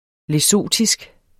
lesothisk adjektiv Bøjning -, -e Udtale [ leˈsoˀtisg ] Betydninger fra det afrikanske land Lesotho; vedr.